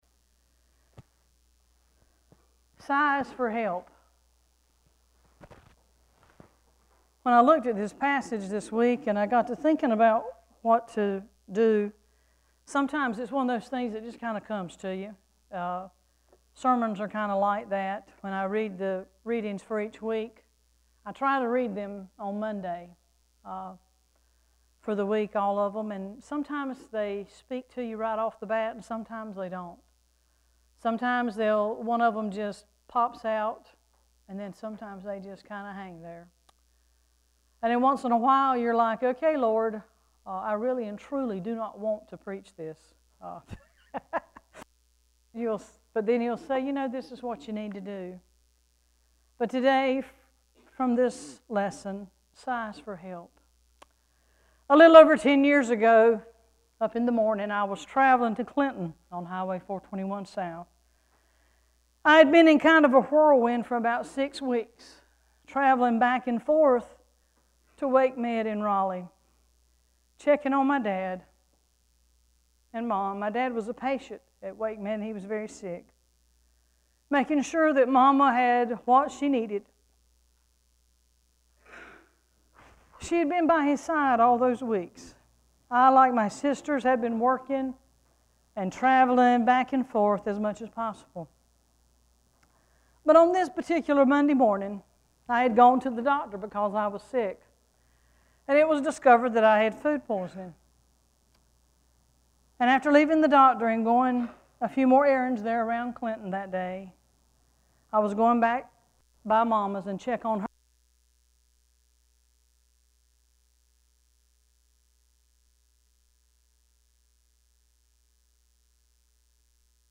This weeks scripture and sermon:
7-27-14-scripture-and-sermon.mp3